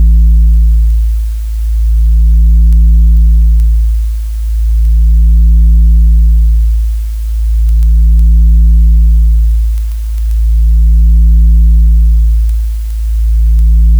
A low, pervasive hum.
I wrote a script to generate a 55Hz sine wave—slightly detuned to create a slow, breathing phase oscillation—and introduced a layer of synthetic dust.
I call this “55Hz Concrete.” It is a digital fabrication of an analog experience.
It uses simple sine waves and noise to mimic the “ghost in the machine.”
# 2. The Texture (Tape Hiss / Air Noise)
# 3. The Imperfections (Dust/Crackle)
It’s not music.
soundscape brutalism generativeart python fieldrecording